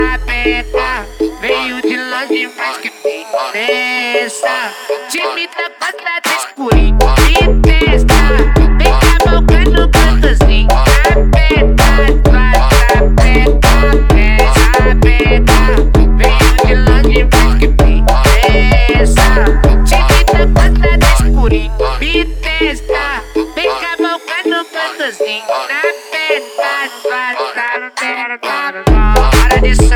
Brazilian Baile Funk
Жанр: R&B / Соул / Фанк